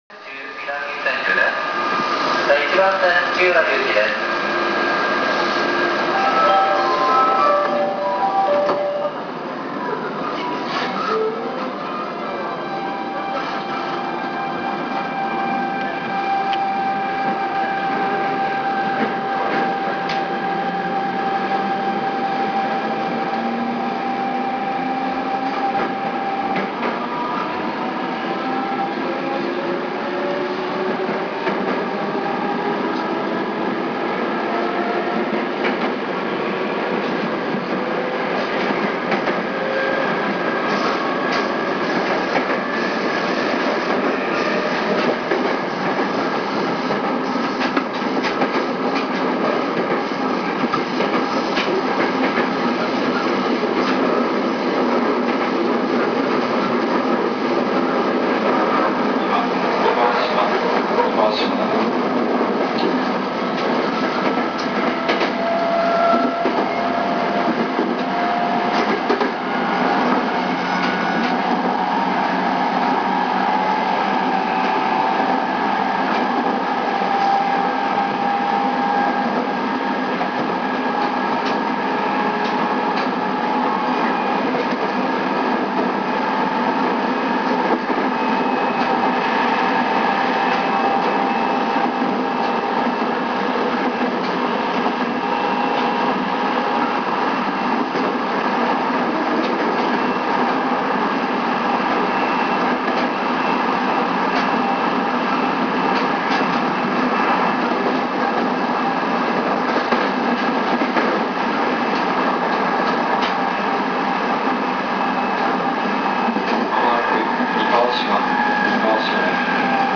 ・E501系(シーメンスGTO車)走行音
【常磐線】南千住→三河島（2分37秒：857KB）
シーメンス製の音階が特徴のGTOインバータ。
転調してしまってからは他の209系統の音と大差なくなります。停車時にも音階が流れるのがE501系の特徴でした。